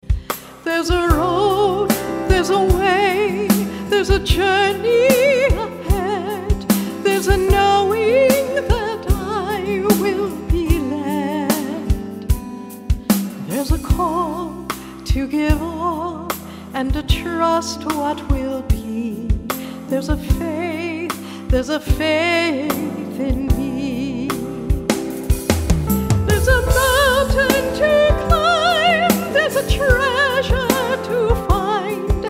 A collection of worship songs